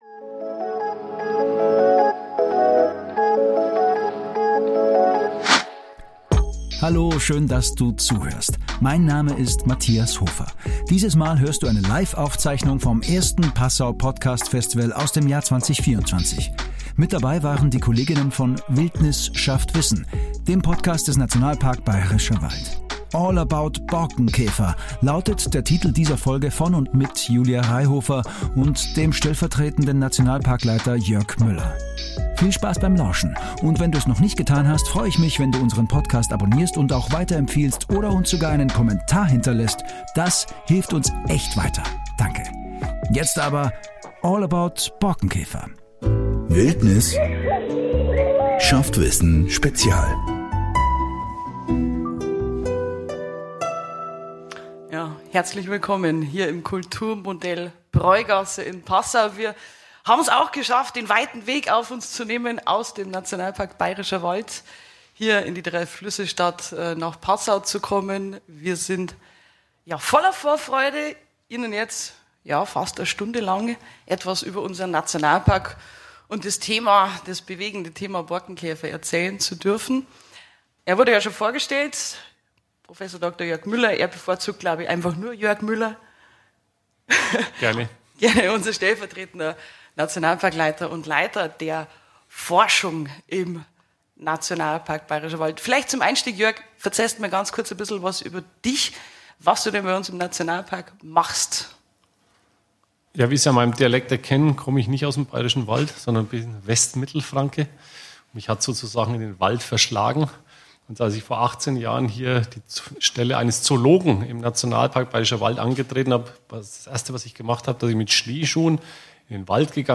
Aufgezeichnet beim ersten Passauer Podcast-Festival im Oktober 2024, haben sich die Kollegen vom Nationalpark Bayerischer Wald mit dem schwierigen Thema Borkenkäfer auseinander gesetzt und detailliert informiert.